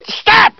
half life stahp Meme Sound Effect